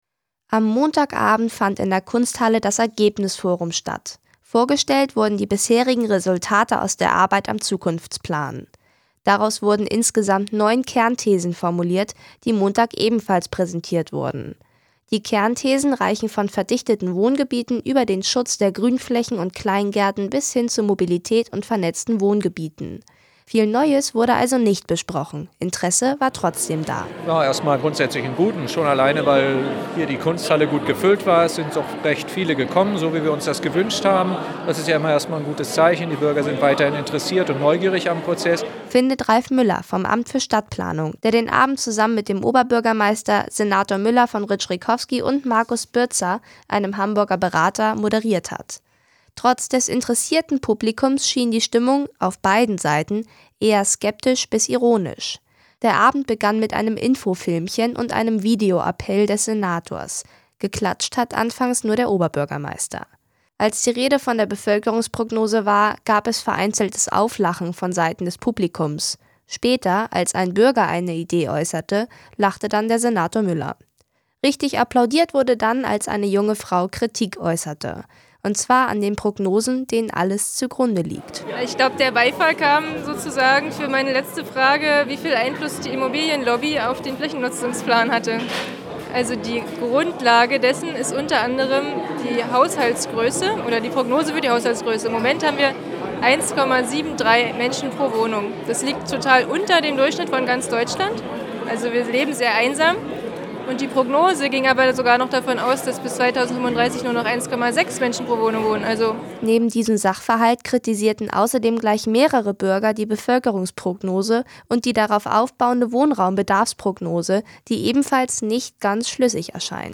Radio zum Nachhören